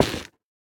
minecraft / sounds / block / stem / break6.ogg
break6.ogg